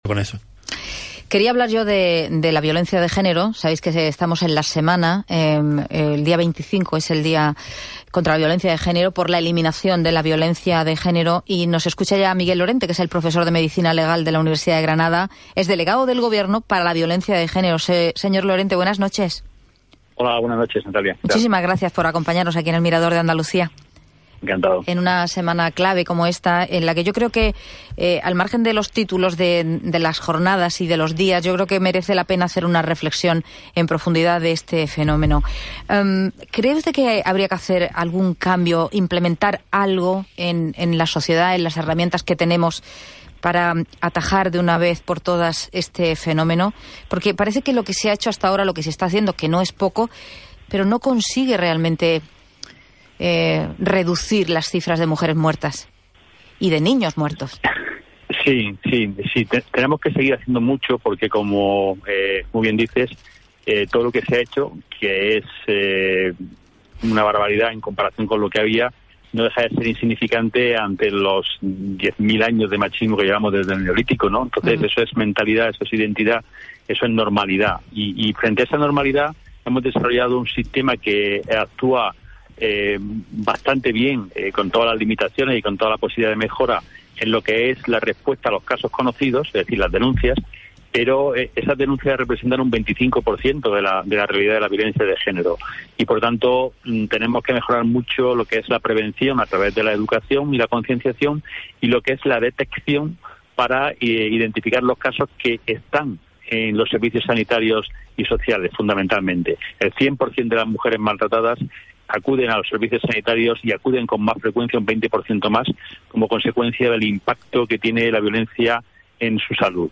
Entrevista a Miguel Lorente con motivo del Día Internacional de la Eliminación de la Violencia contra la Mujer - Canal UGR
El Mirador de Andalucía de Canal Sur Radio entrevista a Miguel Lorente Acosta, profesor de Medicina Legal y asesor de la Unidad de Igualdad del Vicerrectorado de Igualdad, Inclusión y Sostenibilidad de la Universidad de Granada, con motivo de la conmemoración del Día Internacional de la Eliminación de la Violencia contra la Mujer que tendrá lugar el jueves 25 de noviembre.